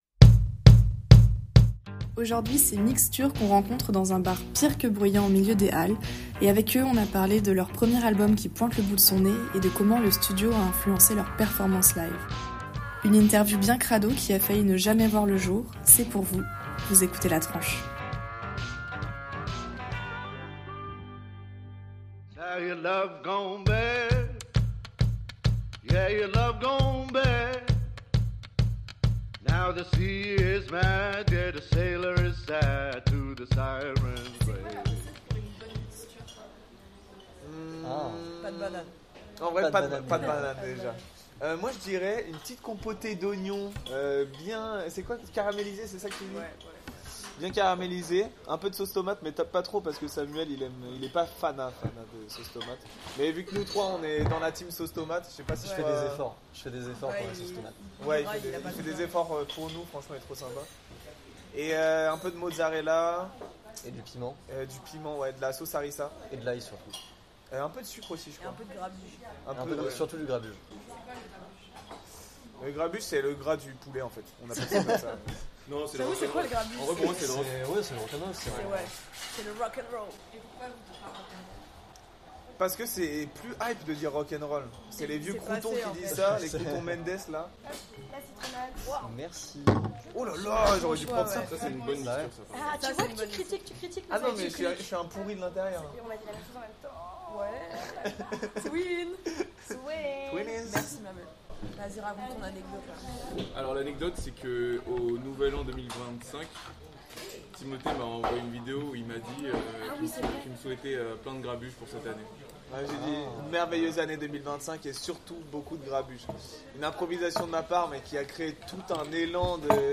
L'interview qui a failli ne jamais voir le jour, et qui a atterri dans un bar de Châtelet.